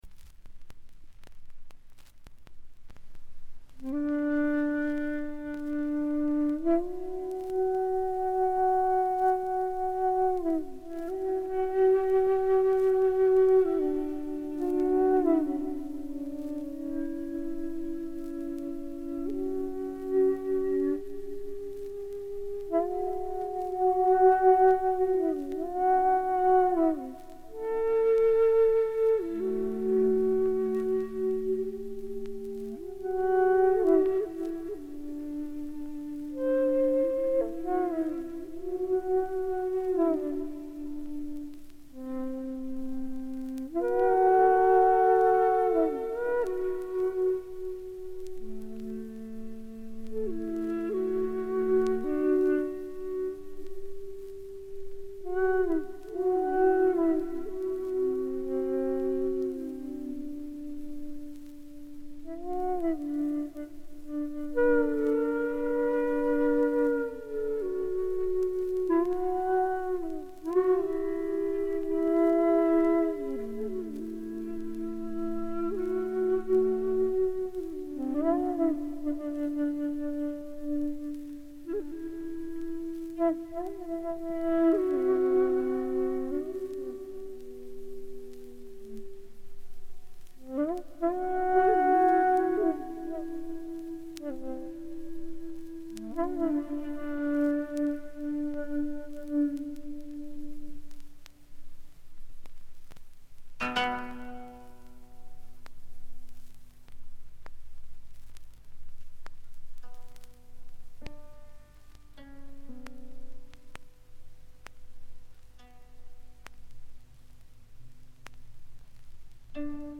静音部で軽微なバックグラウンドノイズ、チリプチ。
試聴曲は現品からの取り込み音源です。
Shakuhachi
Koto
Piano